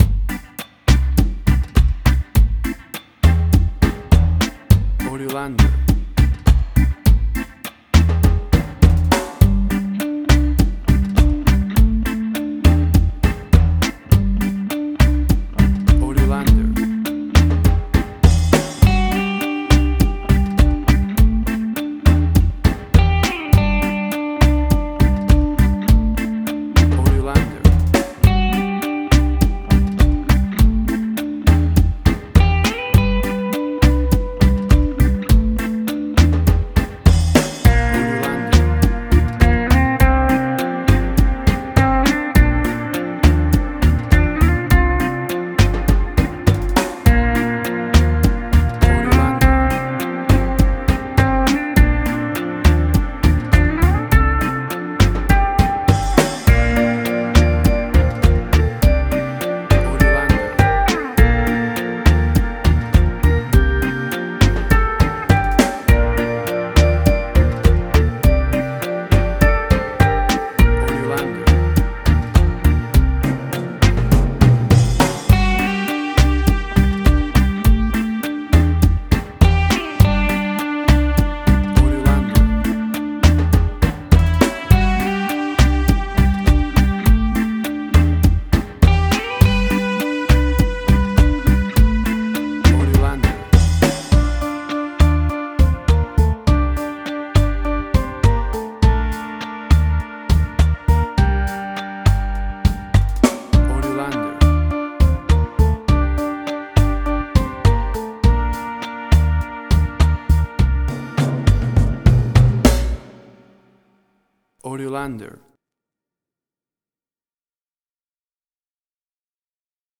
Reggae caribbean Dub Roots
Tempo (BPM): 102